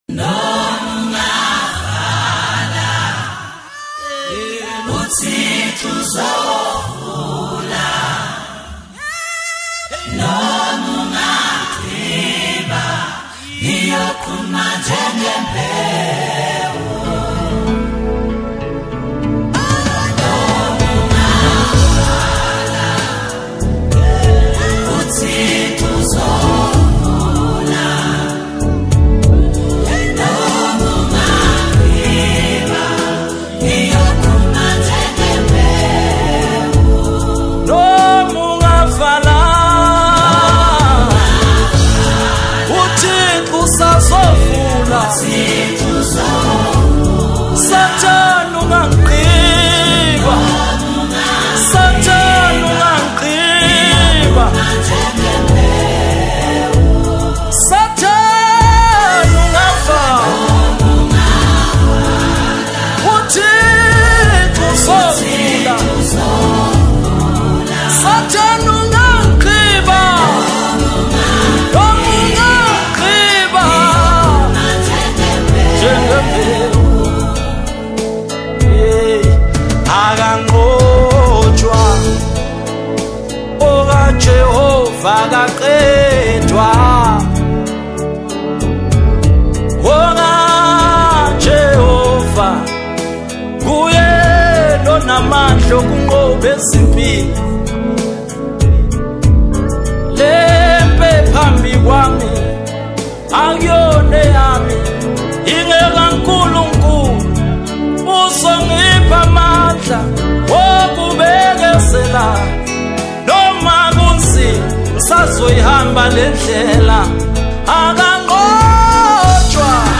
Amapiano Gospel
Genre: Gospel/Christian